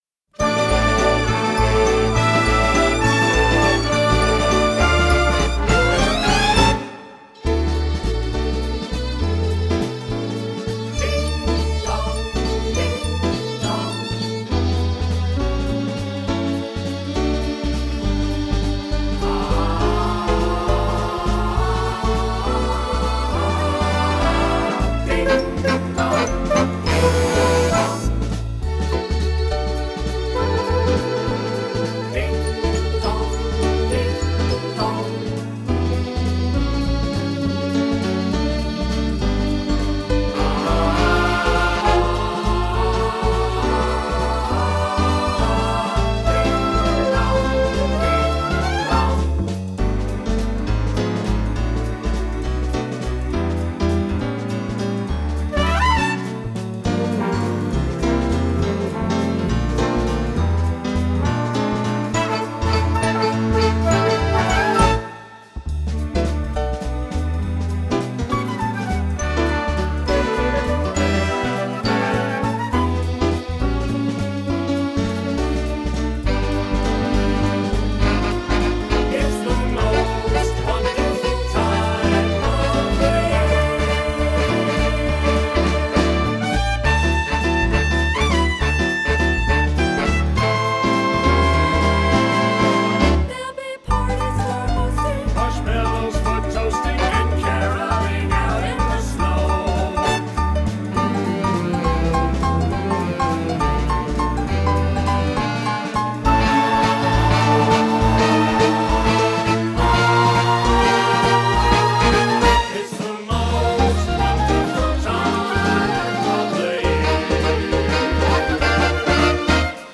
Instrumental w/Backing vocals